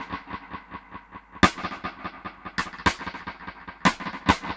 Index of /musicradar/dub-drums-samples/105bpm
Db_DrumsB_EchoSnare_105-01.wav